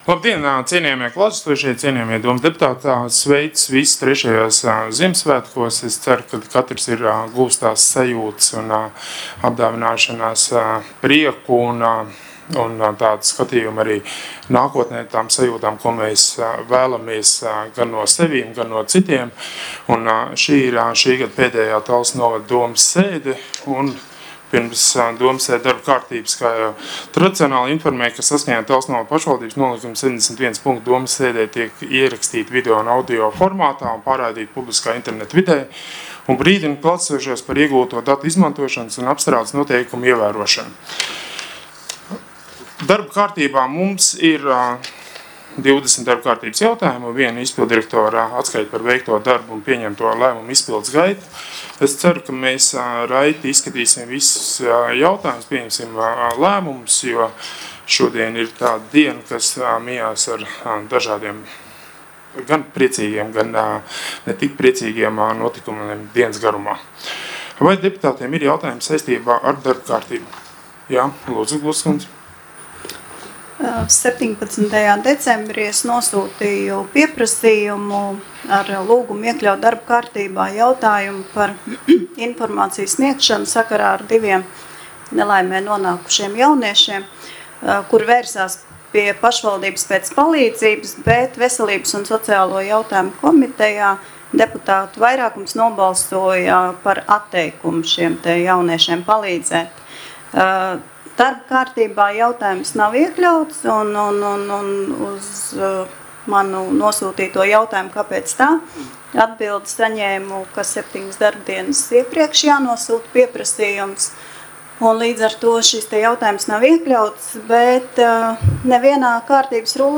Balss ātrums Publicēts: 27.12.2019. Protokola tēma Domes sēde Protokola gads 2019 Lejupielādēt: 26.